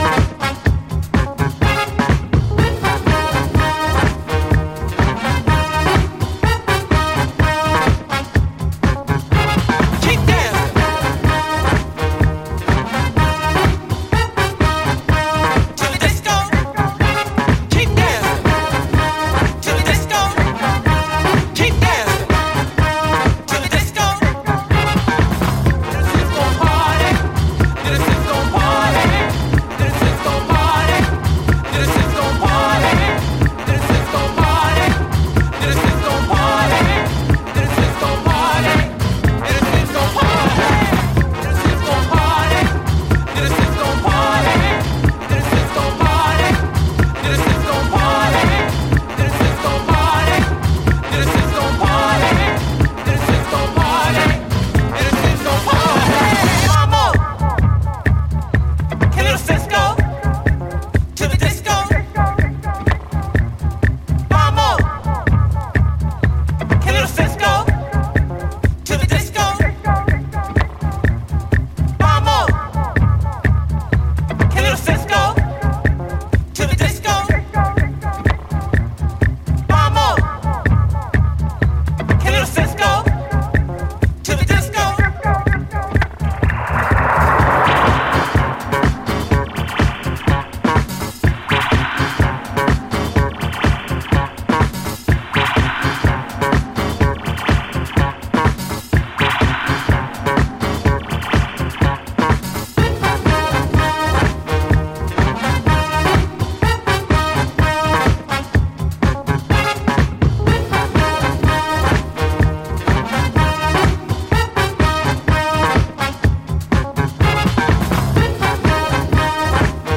unclassic disco-influenced party starters